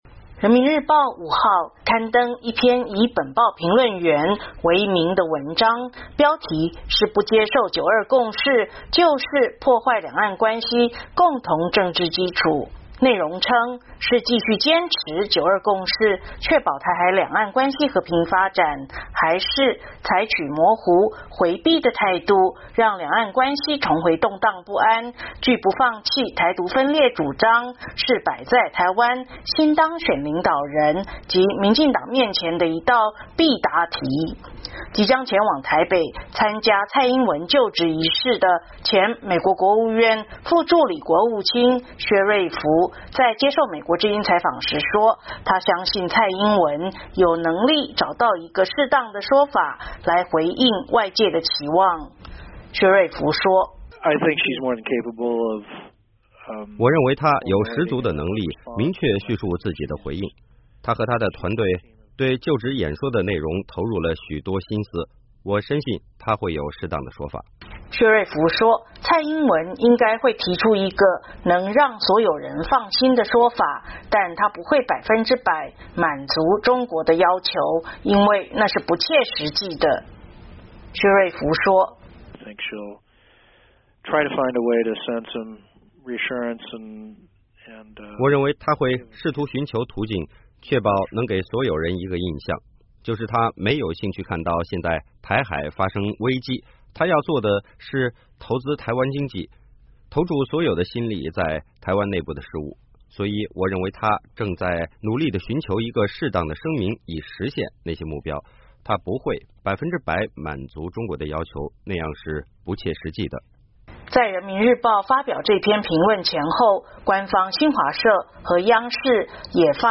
即将前往台北参加蔡英文就职仪式的前美国国务院副助理国务卿薛瑞福(Randall Schriver)在接受美国之音采访时说，他相信蔡英文有能力找到一个适当的说法来回应外界的期望。